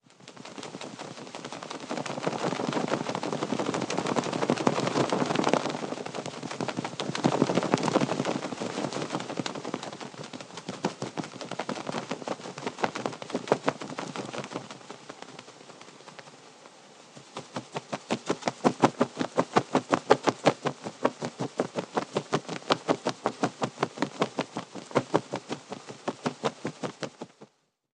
Звук птицы: крылья машут без остановки